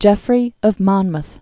(jĕfrē; mŏnməth) 1100?-1154.